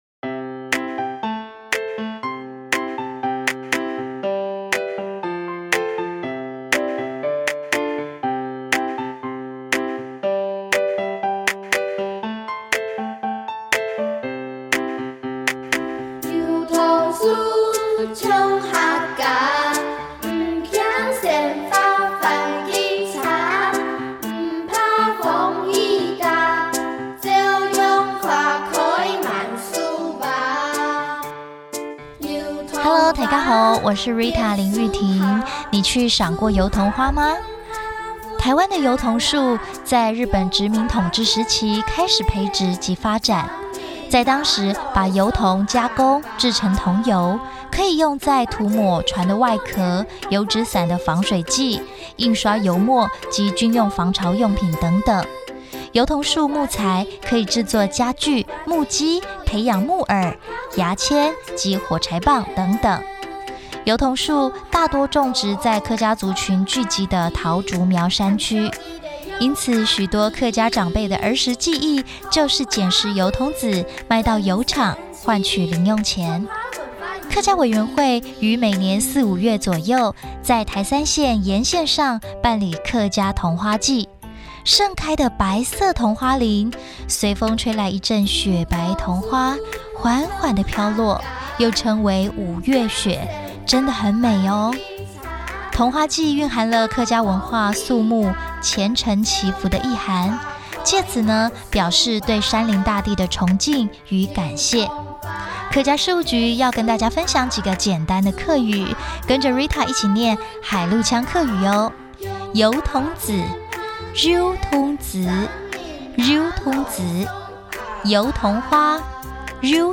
4月油桐花(海陸腔) | 新北市客家文化典藏資料庫